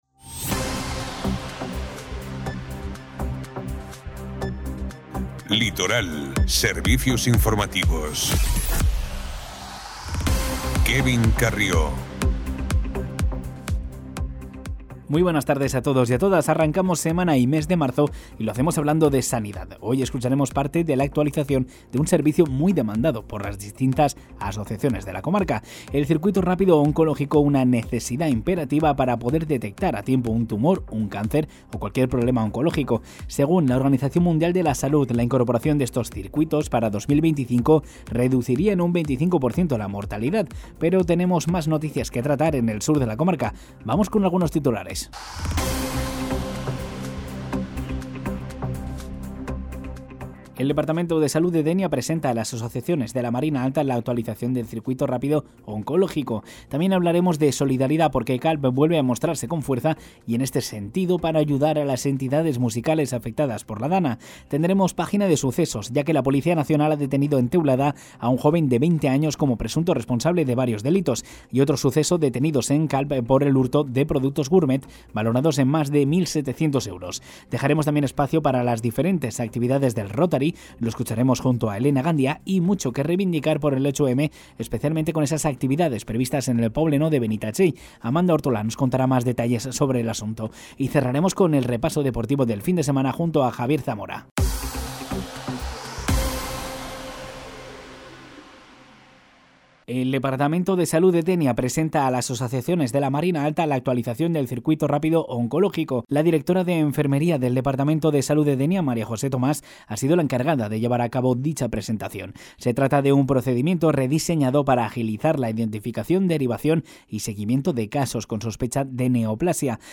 Informativo Ràdio Litoral 03/03/2025 | Ràdio Litoral
Sintoniza las noticias del día con Ràdio Litoral